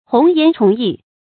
闳言崇议 hóng yán chóng yì
闳言崇议发音
成语注音 ㄏㄨㄥˊ ㄧㄢˊ ㄔㄨㄙˊ ㄧˋ